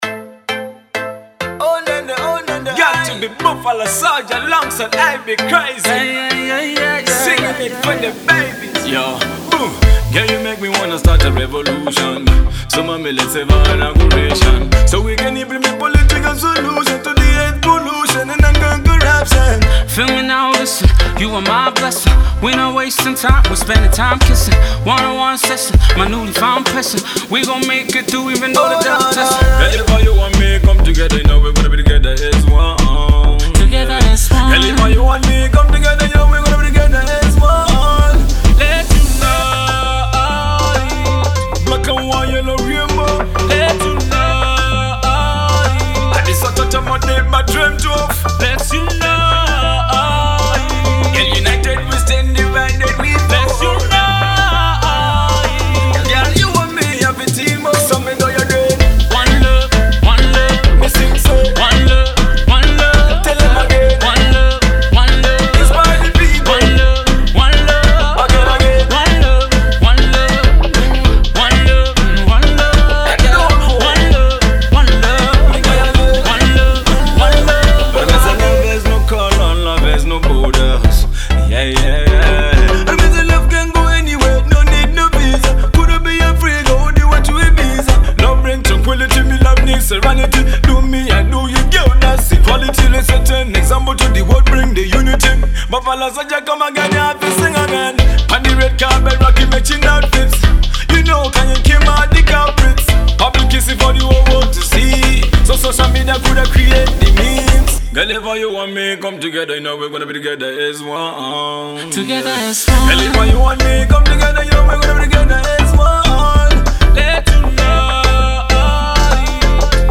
dance-hall tune